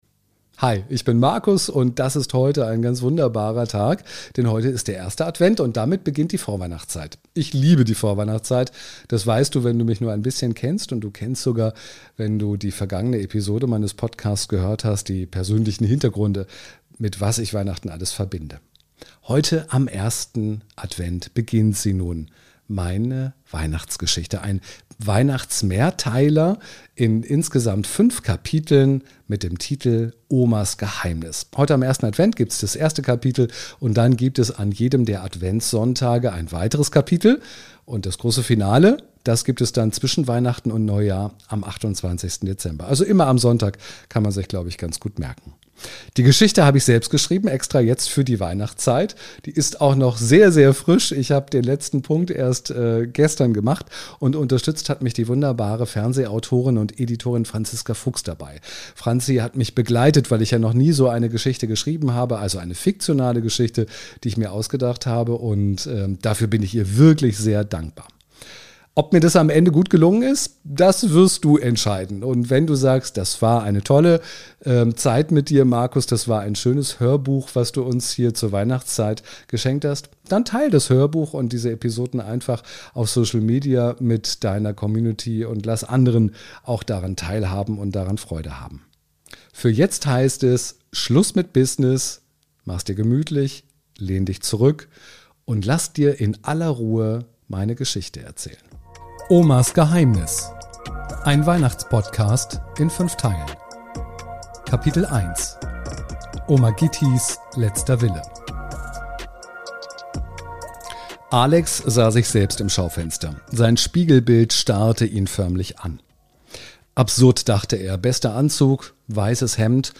Warmherzig, atmosphärisch und geheimnisvoll erzählt – ideal für eine Tasse Tee, ein Stück Rotweinkuchen und ein bisschen Adventsmagie.